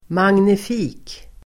Ladda ner uttalet
Uttal: [mangnif'i:k el. manji-]